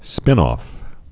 (spĭnôf, -ŏf)